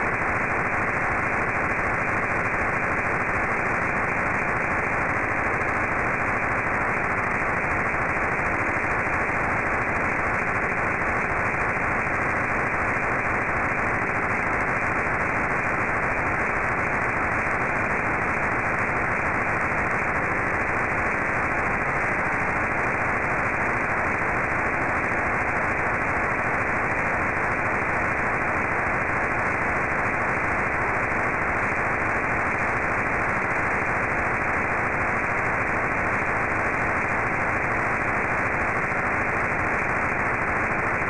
File:P25 trunking channel.mp3